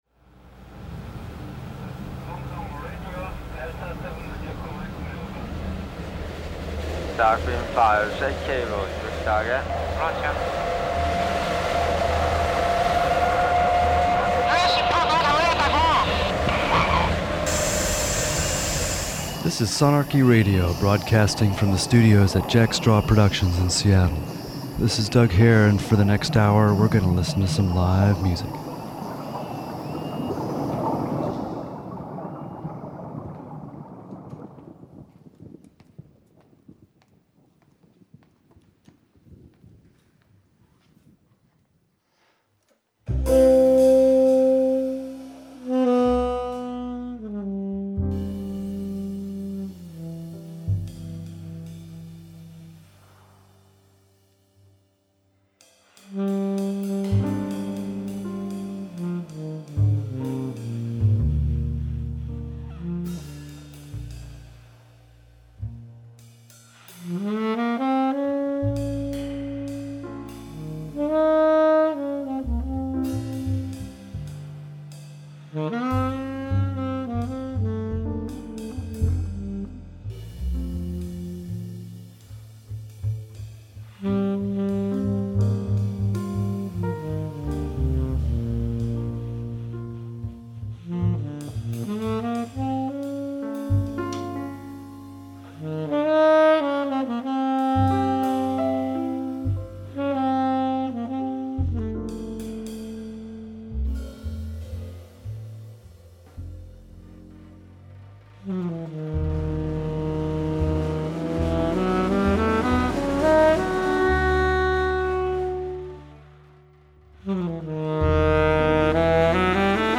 bass
New jazz music from a very tight piano trio
drums